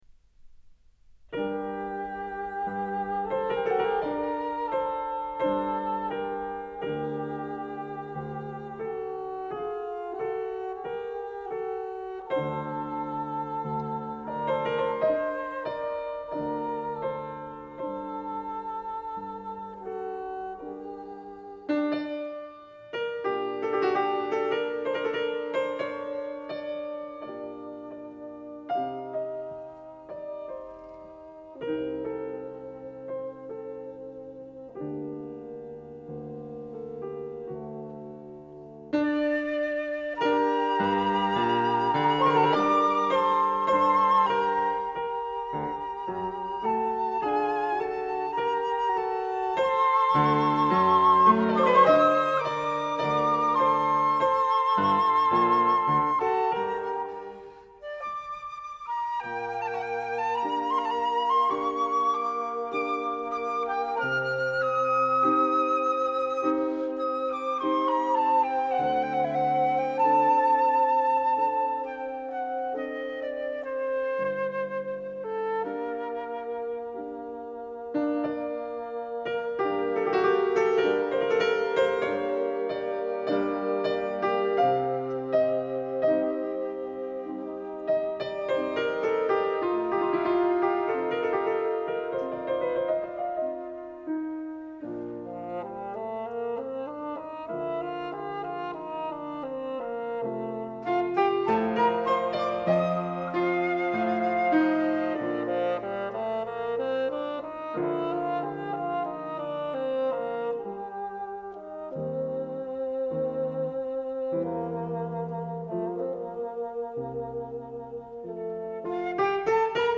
Beethoven: Violin Sonata No. 10 in G, Op. 96 II. Adagio espressivo